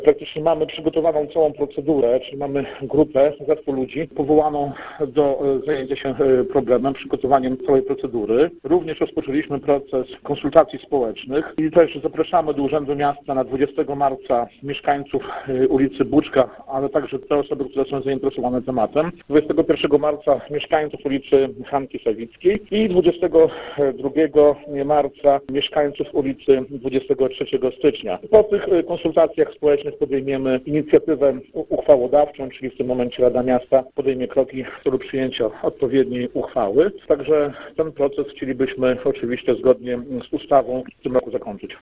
O tym jak zmienią się nazwy zdecydują sami mieszkańcy. – W tym celu organizujemy konsultacje społeczne – mówi Dariusz Latarowski, burmistrz Grajewa.